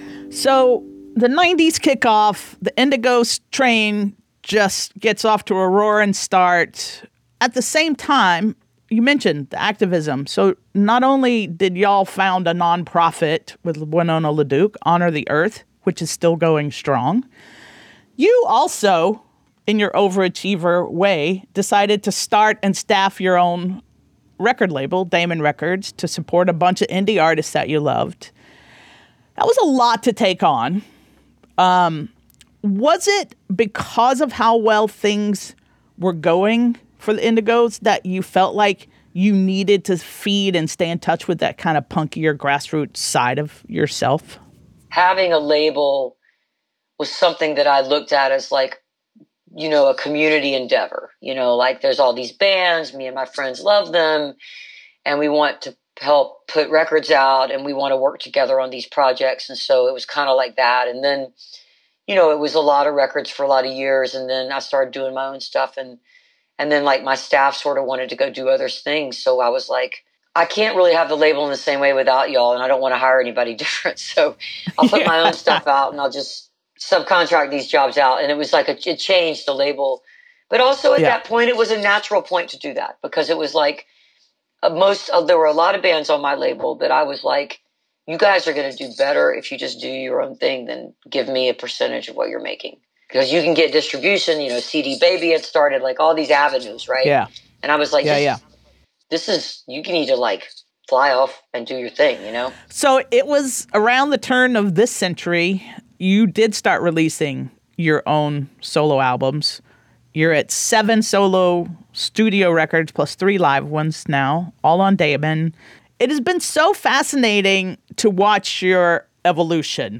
(captured from webcast)
08. interview with amy ray (3:08)